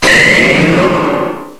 cry_not_mega_venusaur.aif